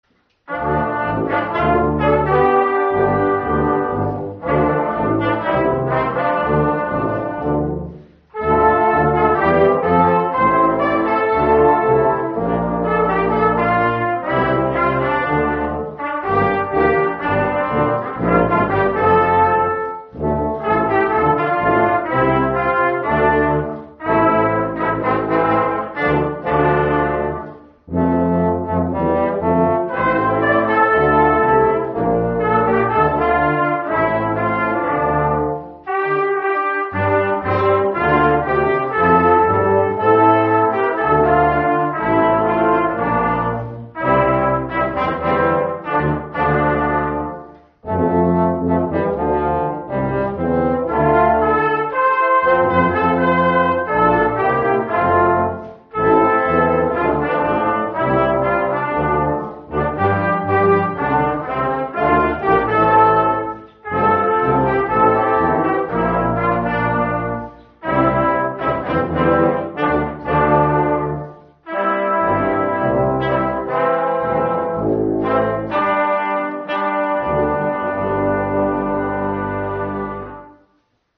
Hier, im ehemaligen Jochen-Klepper-Haus begleiteten wir den traditionellen Oster-Festgottesdienst. Die alten, bekannten, österlichen Weisen ergänzten wir mit einem swingenden Nachspiel zu „Christ ist erstanden“ von Richard Roblee.
09_Nachspiel_Christ_ist_erstanden.mp3